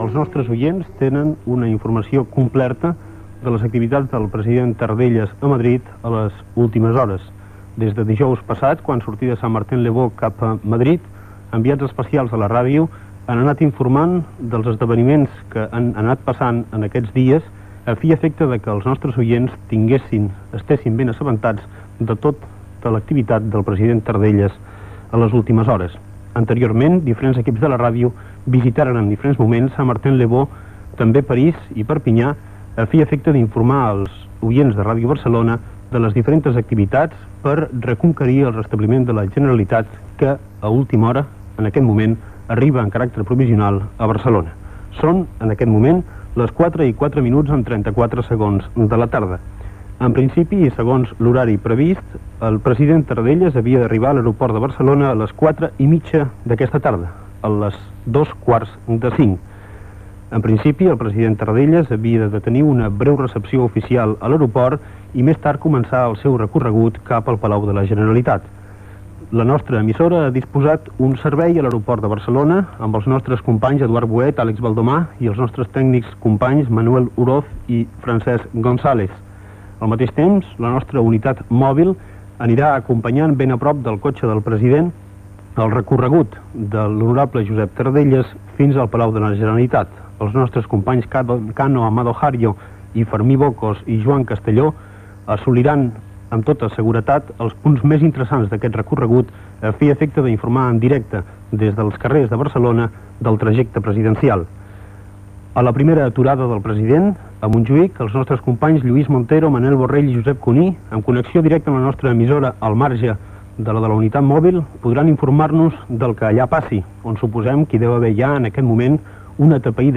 Inici de la transmissió del retorn de l'exili del president de la Generalitat Josep Tarradellas a la ciutat de Barcelona.
Connexió amb el vestívol de l'aeroport del Prat de Barcelona i la unitat mòbil que està a l'exterior. Resum històric de la figura de Josep Tarradellas.
Gènere radiofònic Informatiu